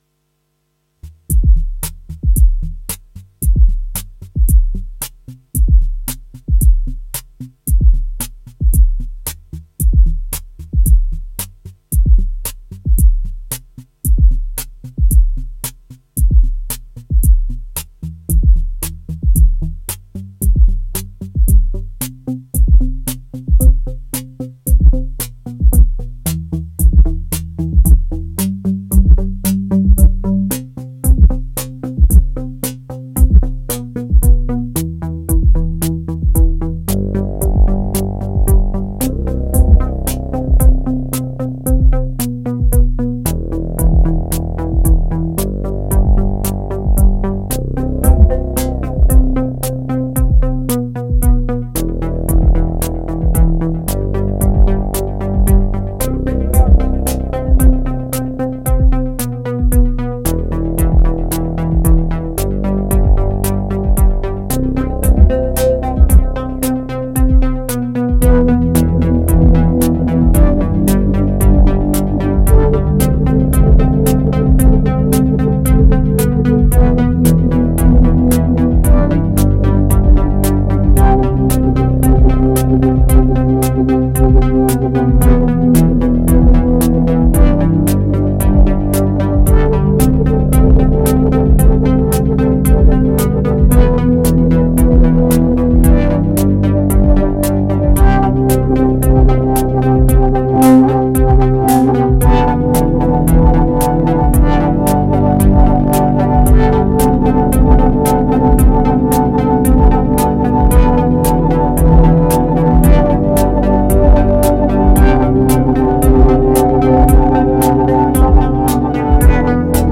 Well….that was hopeful in retrospect I cannot use the octatrack beyond the mixer and effects and scenes which is great for master mixing a track - not yet got into sampling or anything that involves recording so here’s a track put through the ot using fx scenes compress and add interest
It has vibes reminiscent of the avant-garde IDM from the early to mid-'90s.
All the sounds on that track were performed live using machine drum analog four tempest and AS1